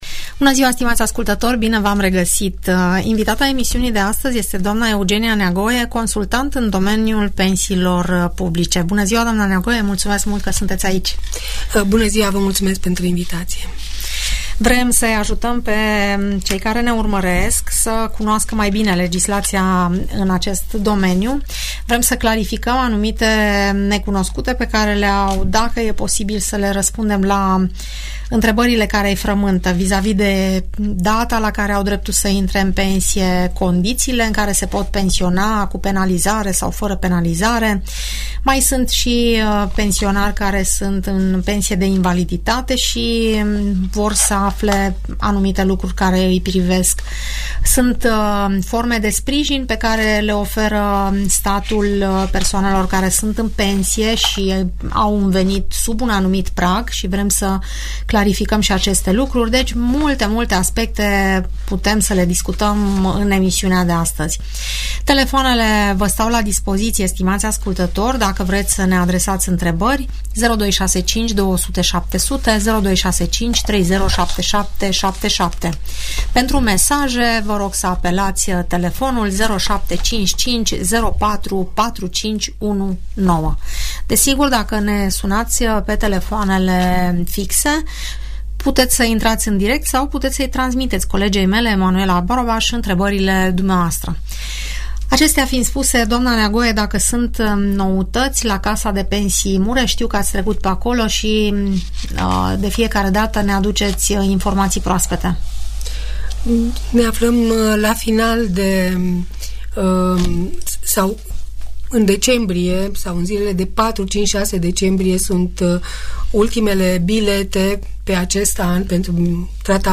» Tot ceea ce trebuie să știi despre pensii și pensionare Tot ceea ce trebuie să știi despre pensii și pensionare Audiență radio cu explicații despre ajutoarele care se acordă pensionarilor cu venituri mici și răspunsuri despre toate tipurile de pensii, în emisiunea "Părerea ta" de la Radio Tg Mureș.